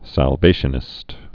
(săl-vāshə-nĭst)